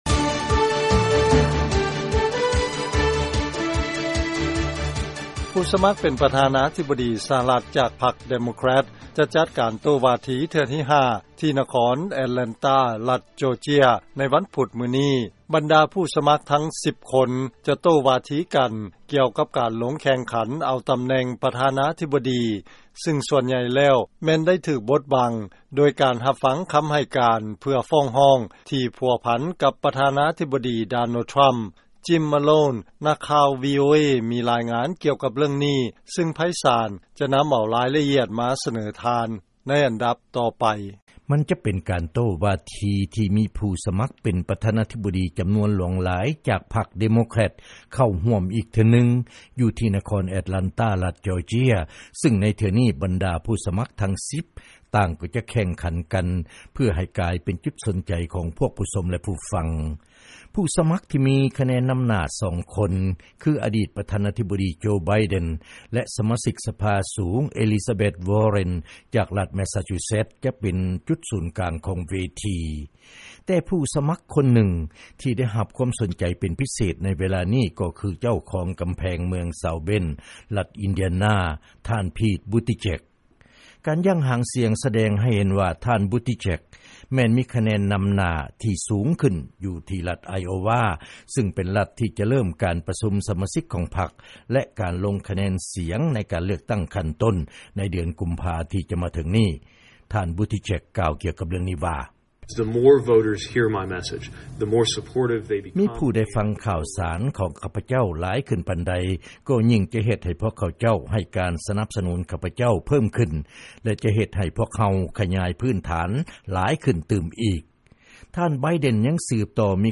ເຊີນຟັງລາຍງານ ຜູ້ສະໝັກ ປະທານາທິບໍດີ ພັກເດໂມແຄຣັດ ກະກຽມ ໂຕ້ວາທີ ທີ່ນະຄອນແອັດແລນຕາ